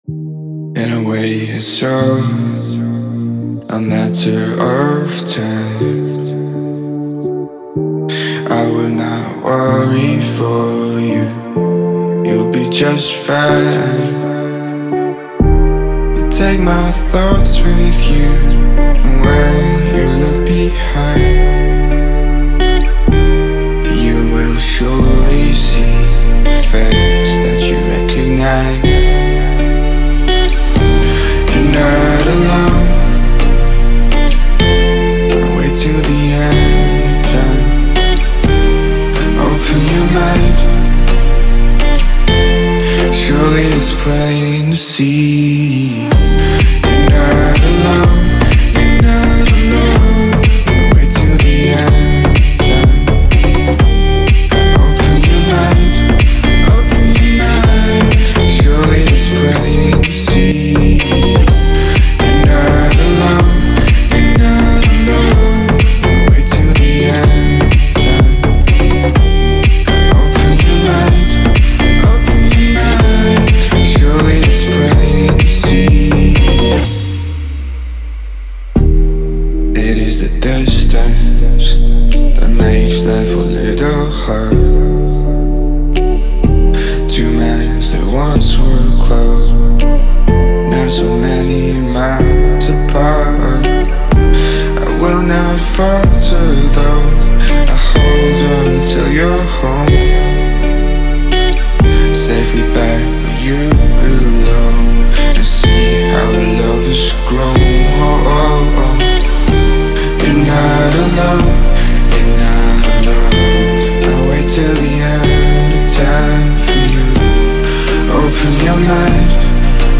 это захватывающий трек в жанре мелодичного хауса